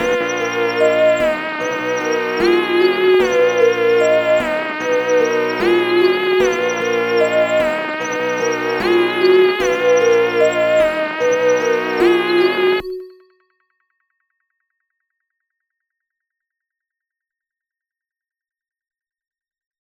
Huncho Dmin 150BPM.wav